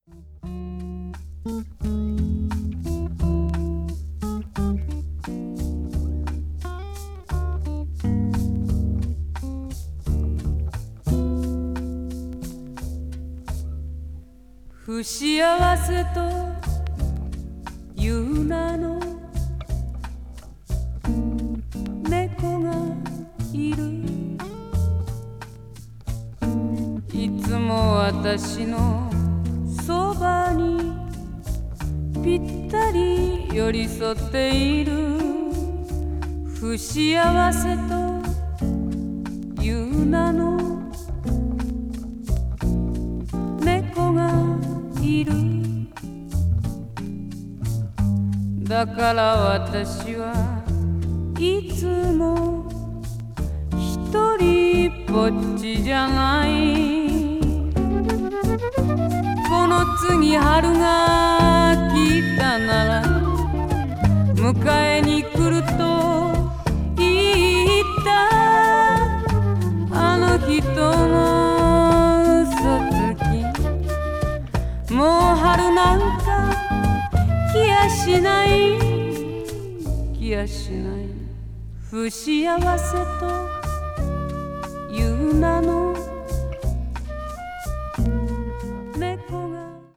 media : EX/EX(わずかにチリノイズが入る箇所あり)
blues   blues rock   folk   folk rock   jazz vocal   soul